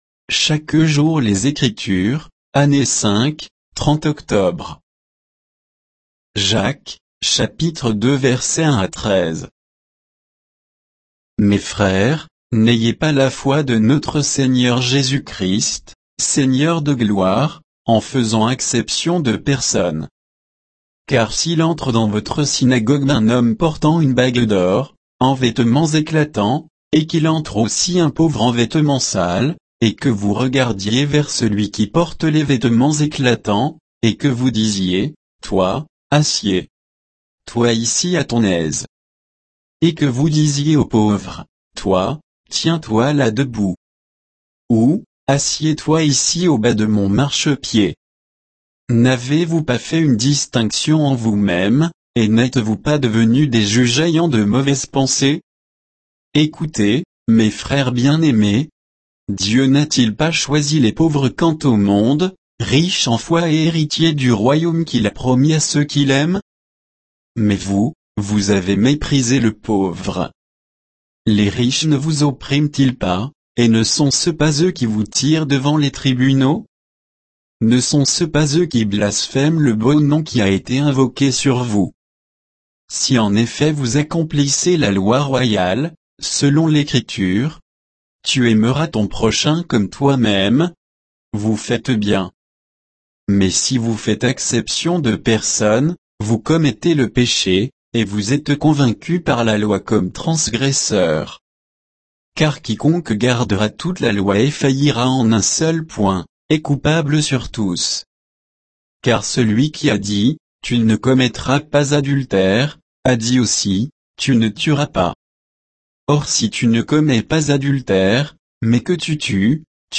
Méditation quoditienne de Chaque jour les Écritures sur Jacques 2